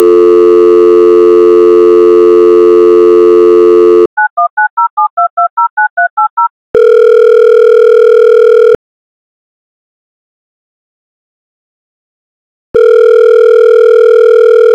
Dialing telephone, own work. made with Audacity
Dialing.ogg